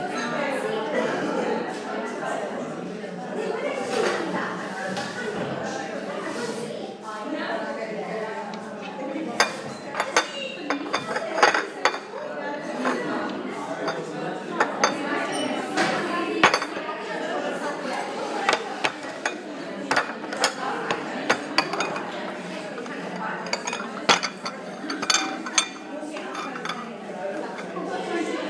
Noisy cafe